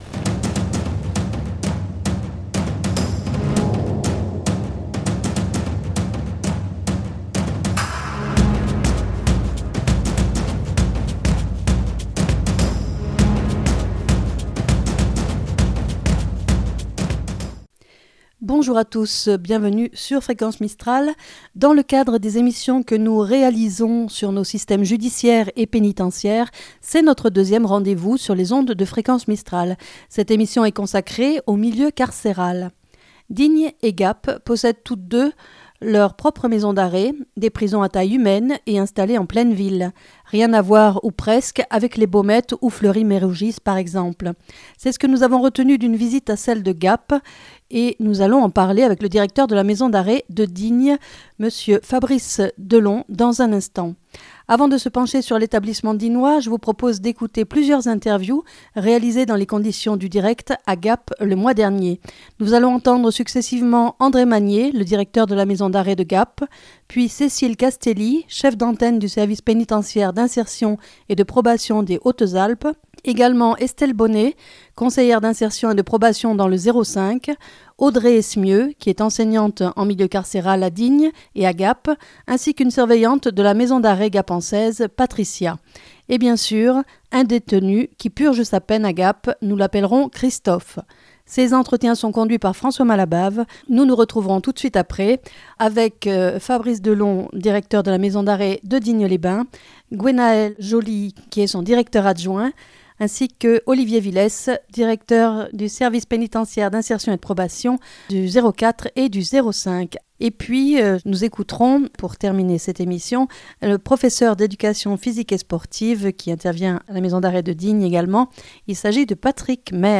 Avant de se pencher sur l’établissement dignois, je vous propose d’écouter plusieurs interviews réalisées dans les conditions du direct à Gap le mois dernier.